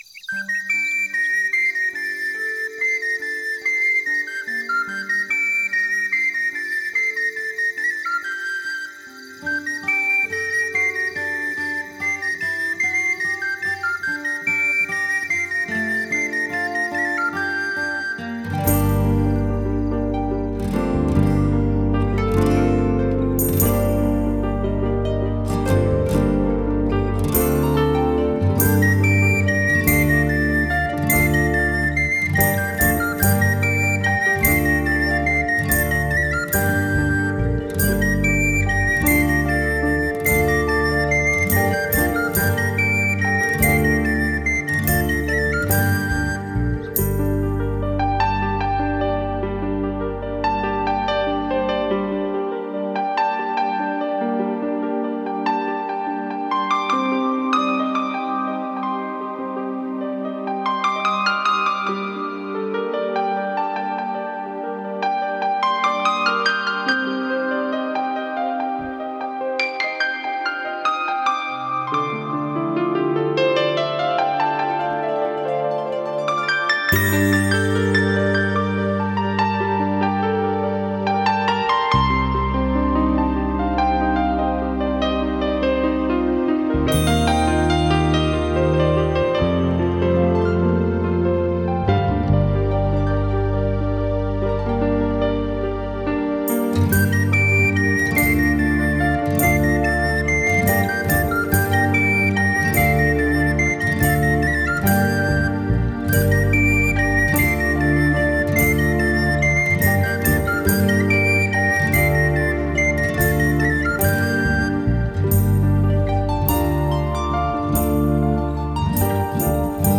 音乐流派；新世纪音乐 (New Age) / 环境音乐 (Ambient Music)
歌曲风格：轻音乐 (Easy Listening) / 纯音乐 (Pure Music)